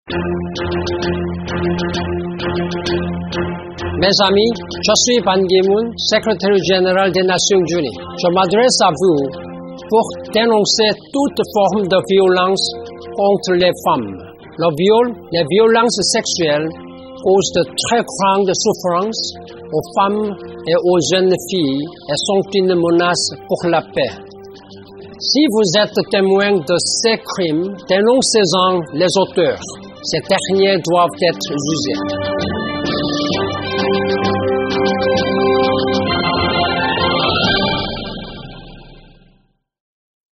Ecoutez ici les messages de Ban Ki-moon, secréteur général de l’ONU, à l’occasion de la campagne de lutte contre les violences faites aux femmes et aux enfants: